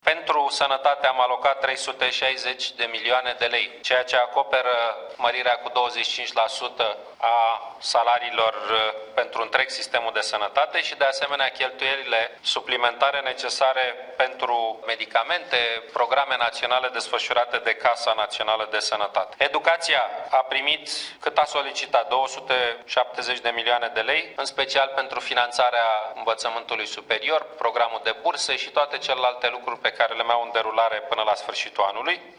Cum au fost împărțiți banii? – iatâ câteva exemple prezentate de premier după ședința de Guvern.
23oct-15-Ponta-bani-la-sanatate-si-educatie.mp3